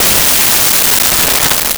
Crash 1
Crash_1.wav